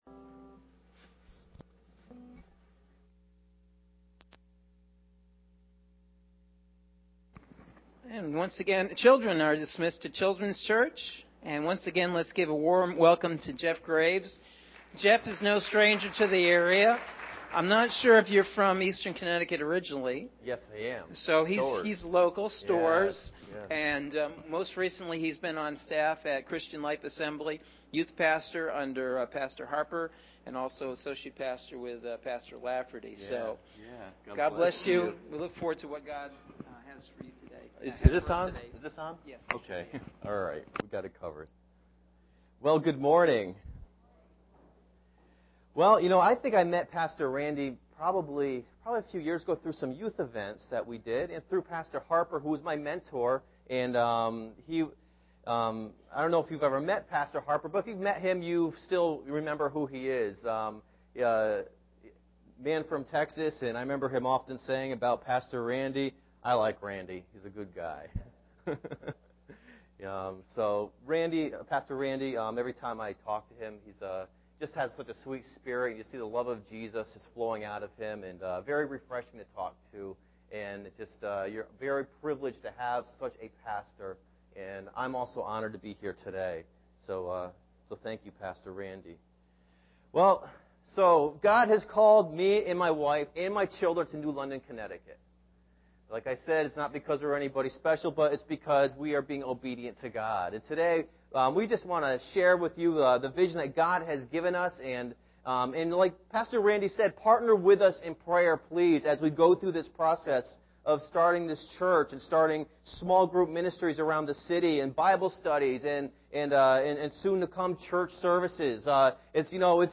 Sunday June 14th – AM Sermon – Norwich Assembly of God